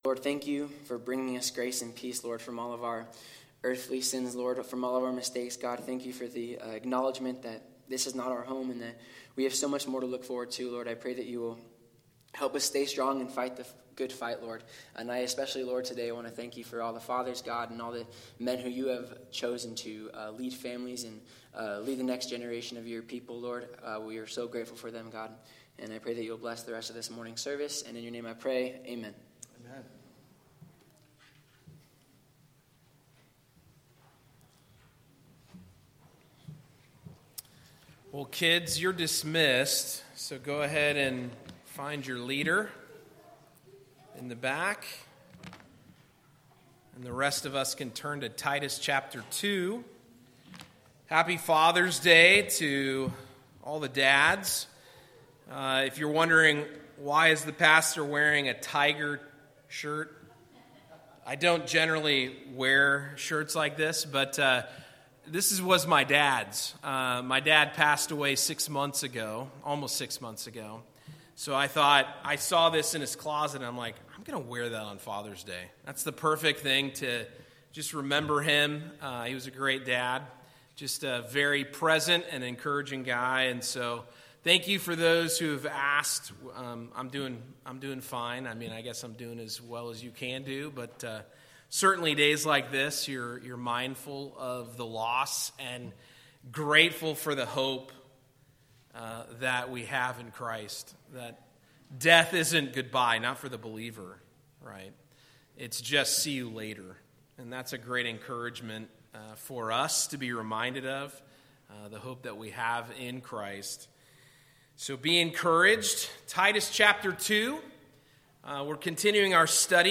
Passage: Titus 2:1-3 Service Type: Sunday Morning